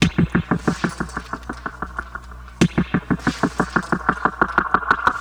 Back Alley Cat (Pecussion FX 02).wav